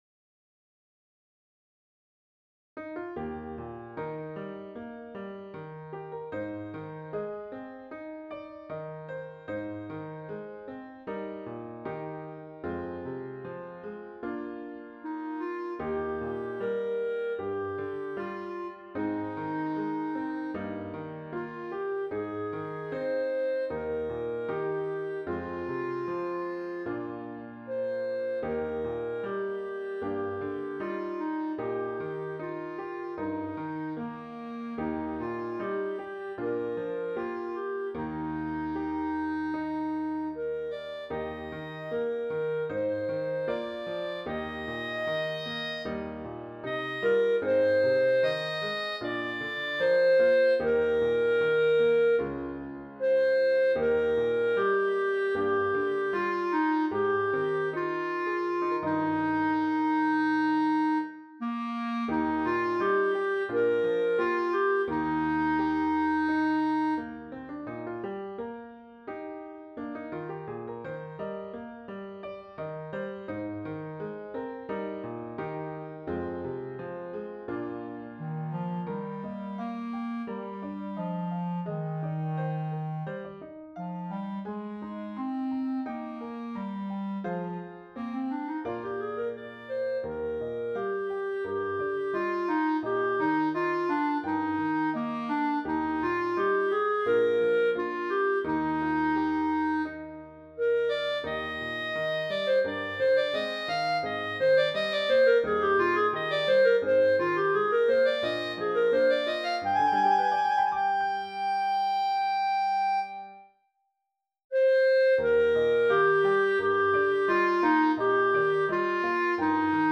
Intermediate Instrumental Solo with Piano Accompaniment.
Christian, Gospel, Sacred, Folk.
put to a flowing folk setting.